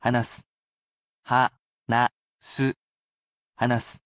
I am sure to repeat the main word(s) slowly, but I read the sentences at a natural pace, so do not worry about repeating after the sentences.